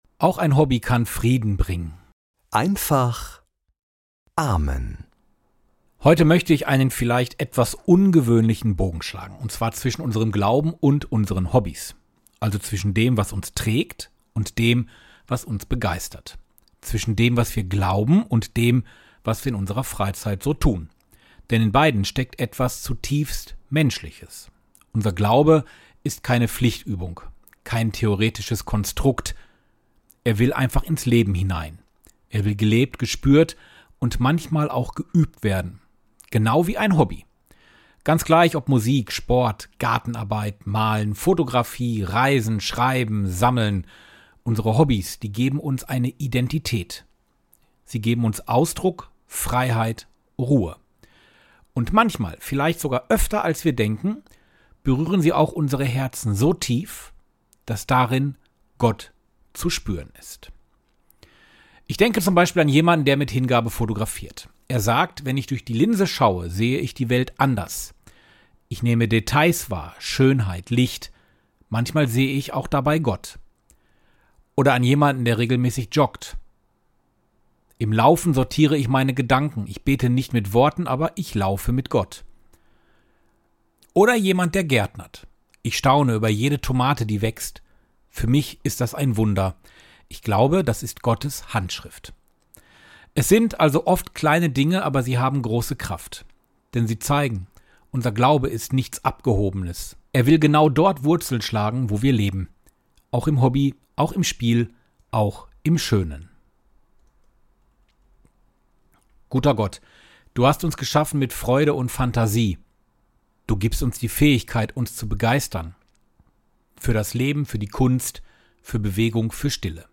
Morgenimpuls in Einfacher Sprache